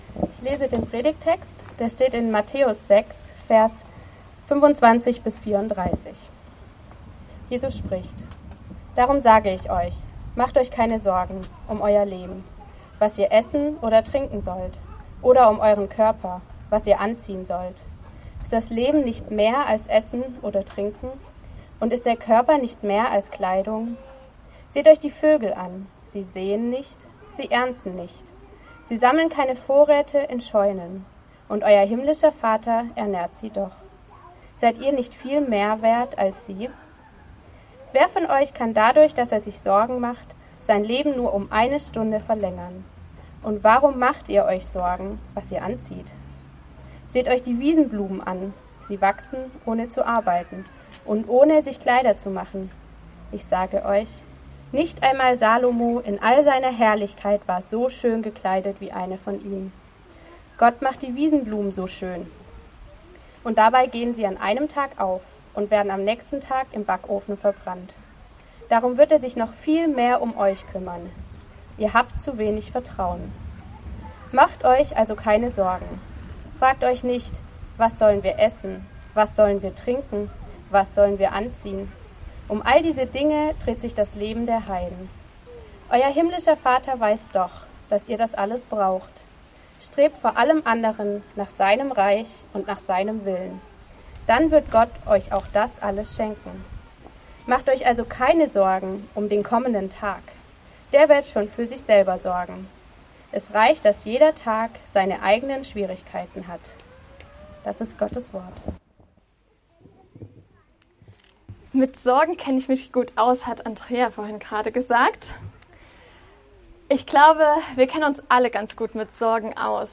Eine predigt aus der serie "GreifBar+." ind wir nicht ganz schöne Sorgenmenschen?